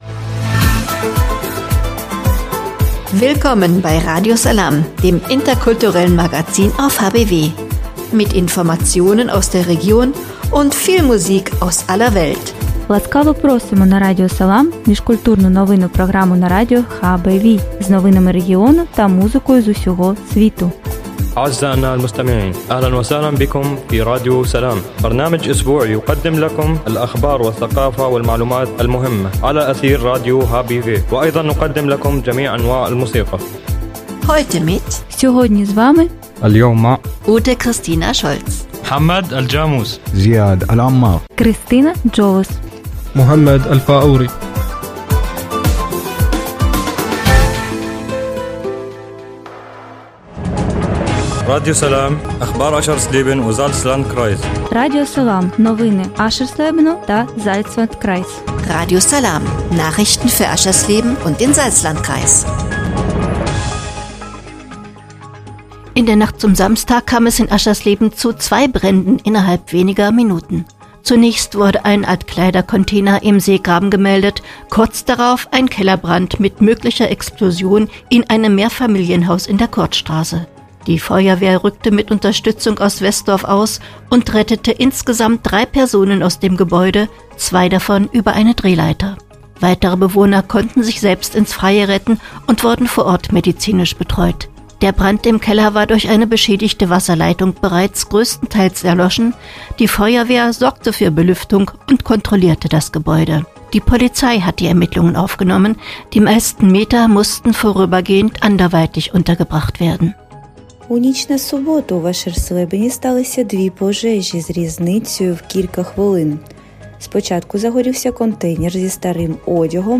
„Radio Salām“ heißt das interkulturelle Magazin auf radio hbw.